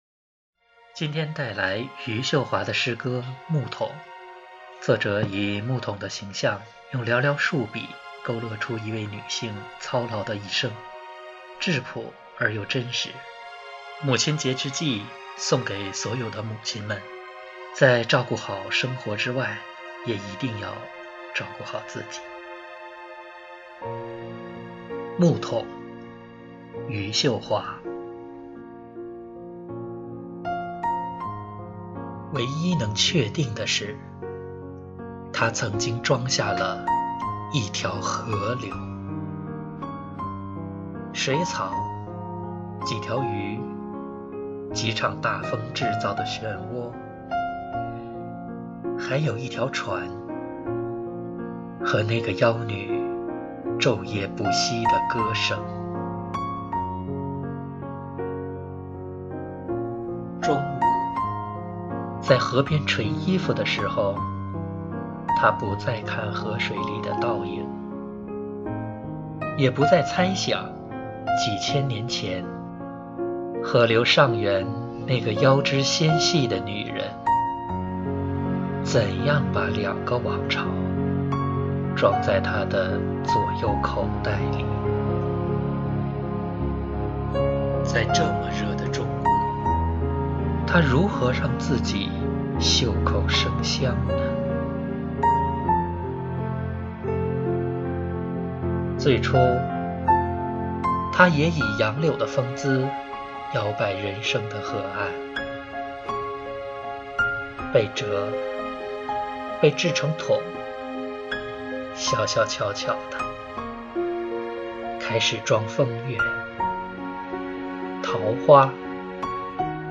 生活好课堂幸福志愿者中国钢研朗读服务（支）队第十一次云朗诵会在五月开启，声声朗诵、篇篇诗稿赞颂红五月，讴歌美好生活，吟诵美丽中国。
《木桶》朗诵